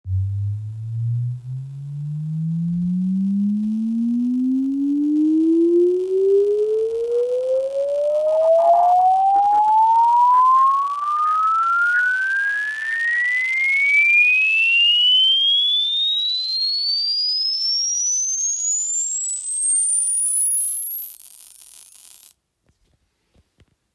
So… for some time now I am ignoring some weird sounds that got pretty apparent when I disabled the high pass filter on my Yamaha HS7 (don’t ask why it was on)…